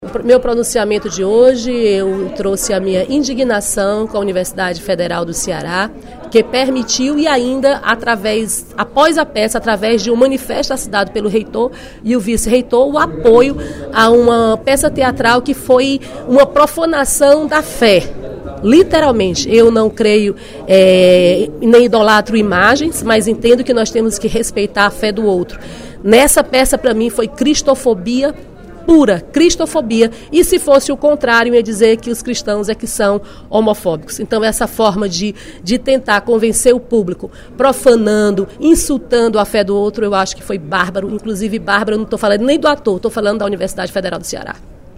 A deputada Dra.Silvana (PMDB) criticou, no primeiro expediente da sessão plenária desta terça-feira (31/05), a peça de teatro apresentada durante seminário sobre sexualidade e gênero, no bloco de Psicologia da Universidade Federal do Ceará (UFC).